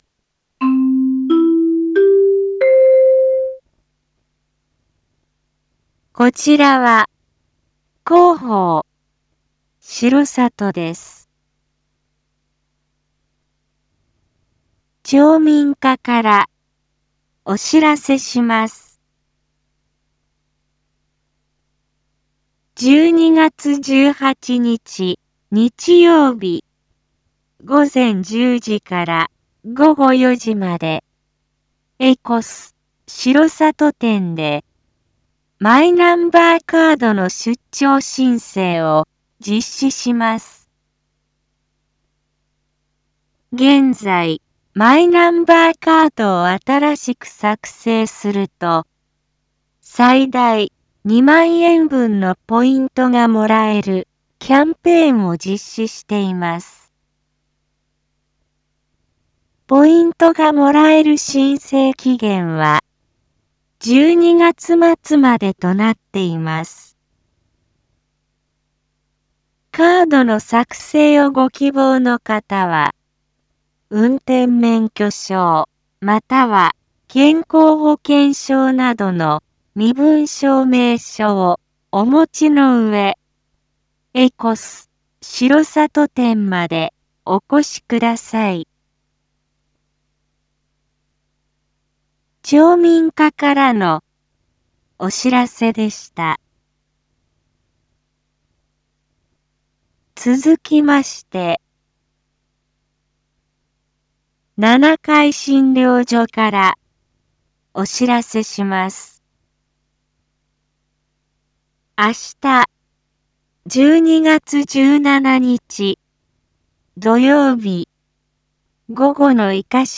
一般放送情報
Back Home 一般放送情報 音声放送 再生 一般放送情報 登録日時：2022-12-16 19:02:36 タイトル：R4.12.16 夜 インフォメーション：こちらは、広報しろさとです。